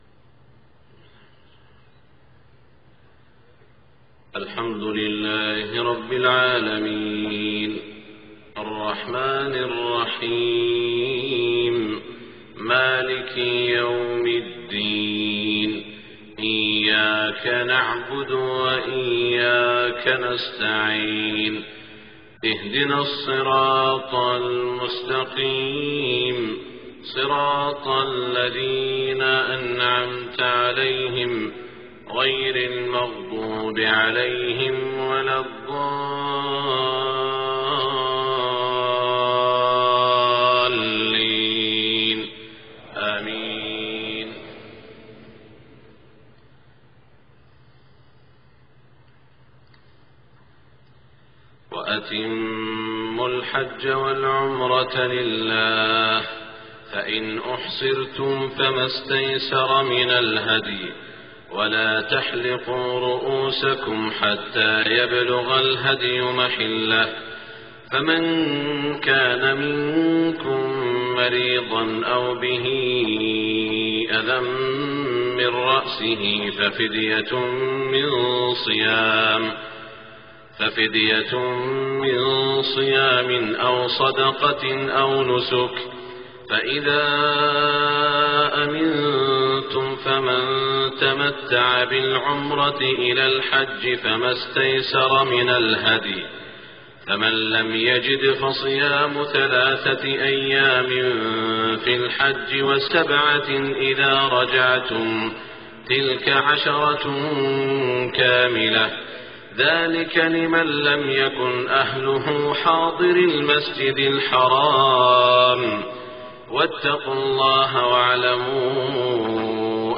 صلاة الفجر 8-6-1427 من سورة البقرة > 1427 🕋 > الفروض - تلاوات الحرمين